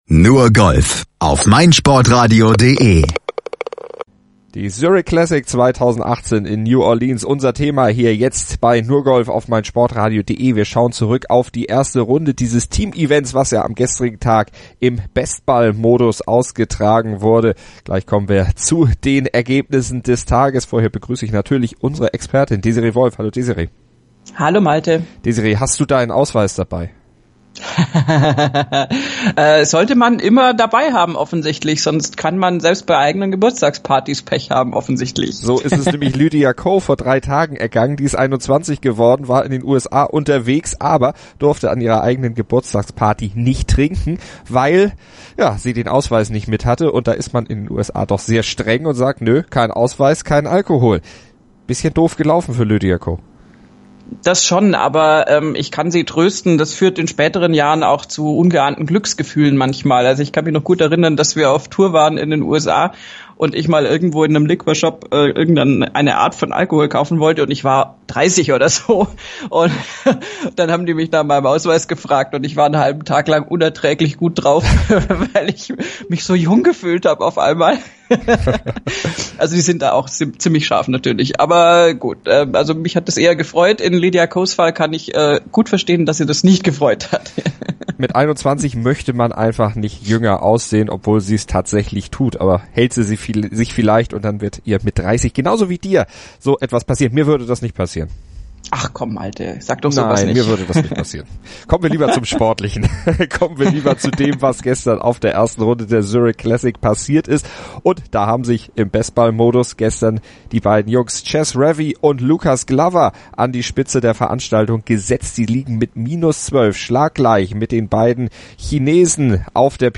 Sie sitzen im sicheren Studio und analysieren Tag 1 der Zurich Classic, der von Chez Reavie und Lucas Glover sowie Xinjun Zhang und Zecheng Dou bestimmt wurde. Diese beiden Duos liegen beim Teamevent mit -12 in Führung.